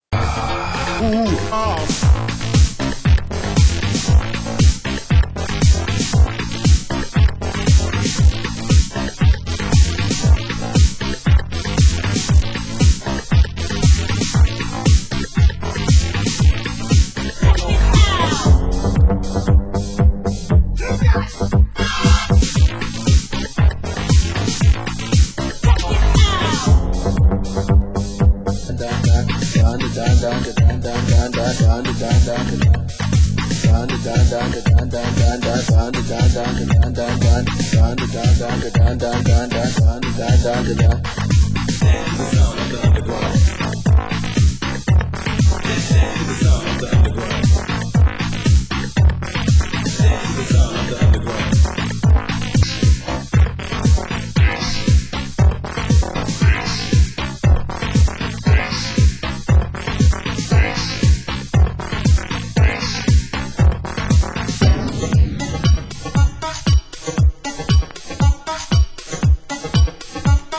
Genre: Euro House